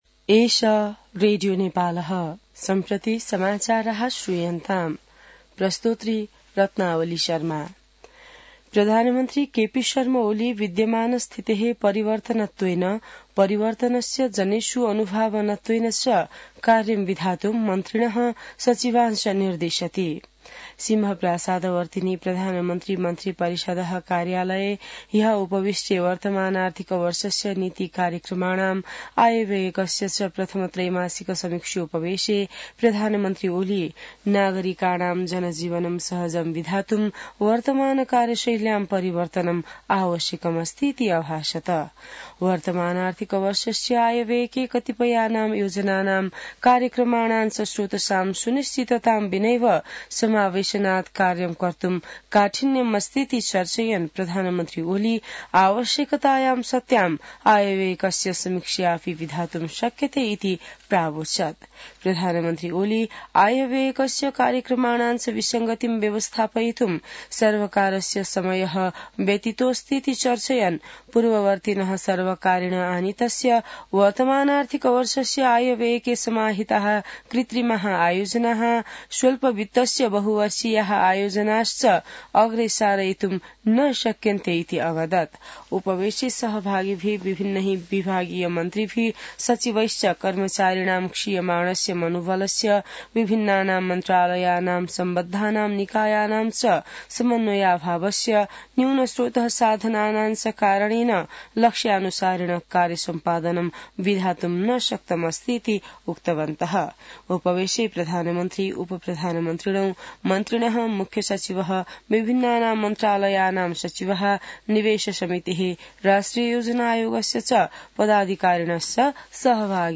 संस्कृत समाचार : १४ मंसिर , २०८१